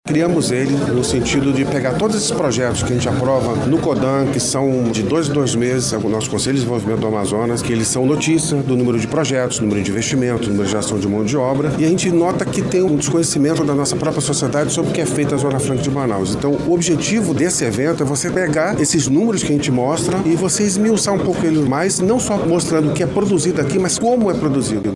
Gustavo Igrejas, secretário-executivo da Secretaria de Desenvolvimento Econômico, Ciência, Tecnologia e Inovação do Amazonas – Sedecti, explica que o evento busca mostrar para a população amazonense, a importância da Zona Franca de Manaus – ZFM.